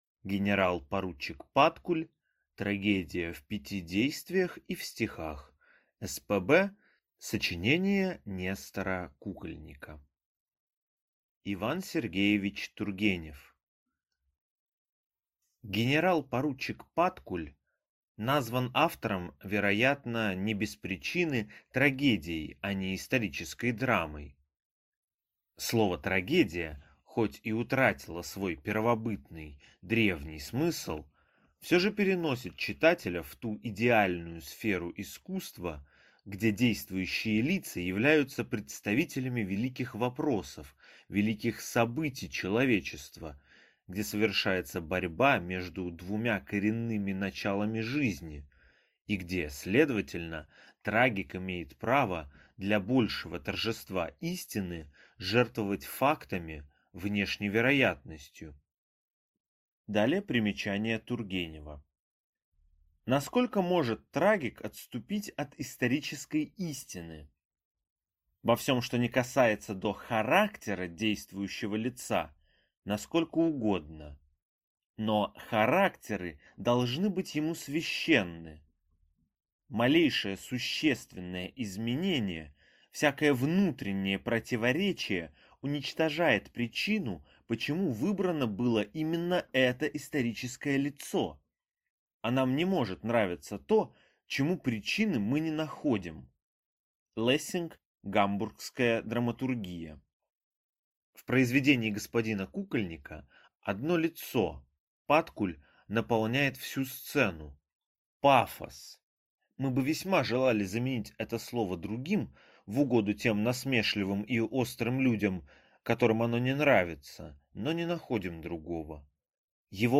Аудиокнига Генерал-поручик Паткуль. Соч. Нестора Кукольника | Библиотека аудиокниг